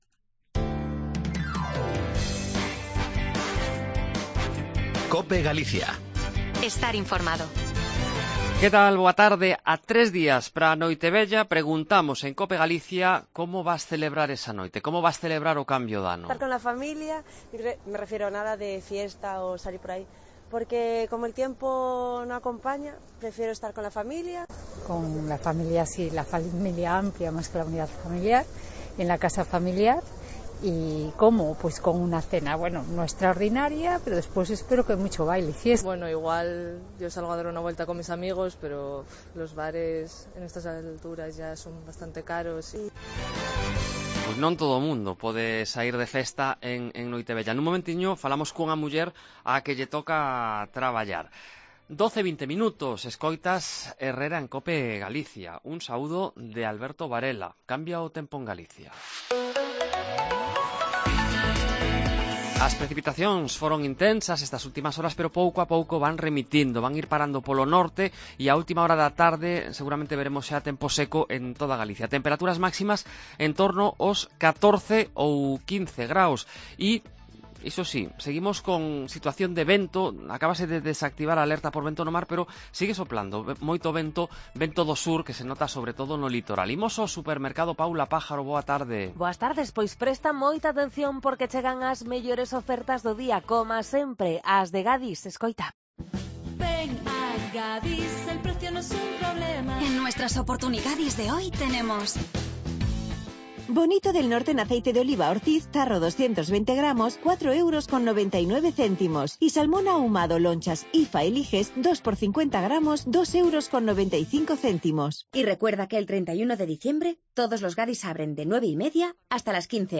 En la cuenta atrás para la celebración del Fin de Año, bajamos los micrófonos de Cope a la calle para saber qué planes tenemos para ese día.